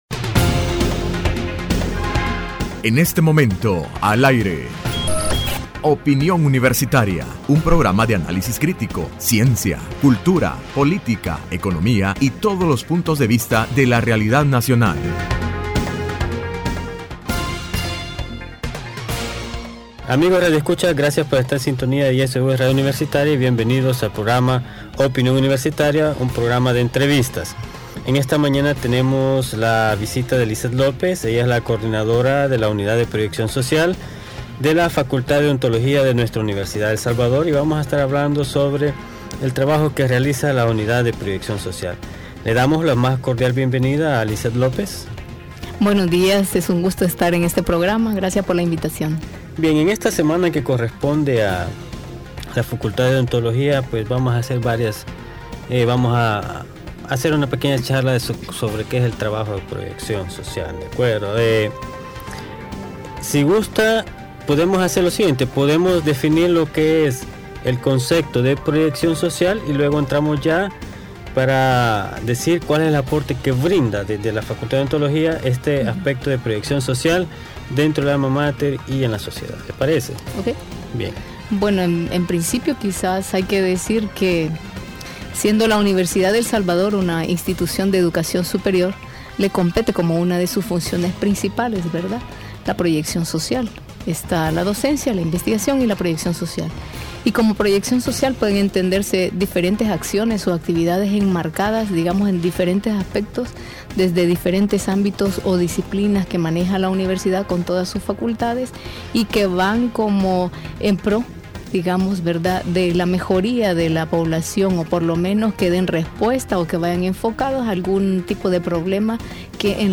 Entrevista Opinión Universitaria (12 de mayo 2015) : Trabajo de la Unidad de Proyección Social de la Facultad de Odontología